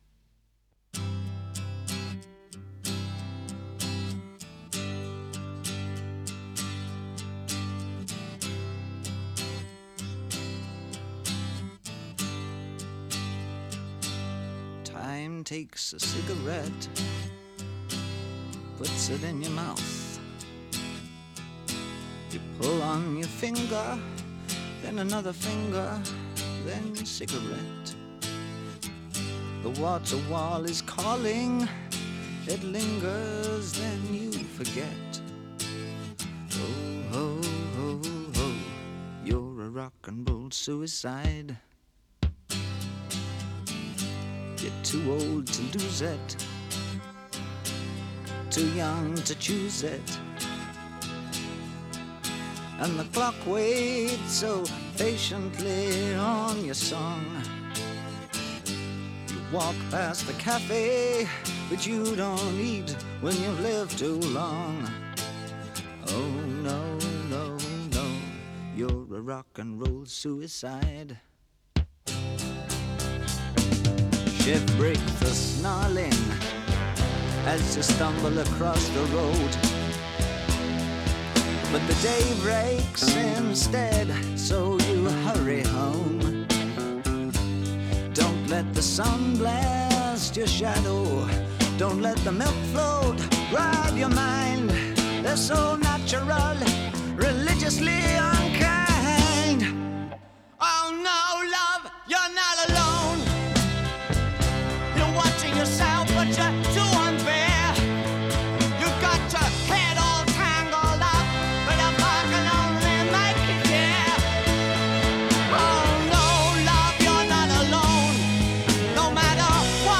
Жанр: Art Rock, Glam-Rock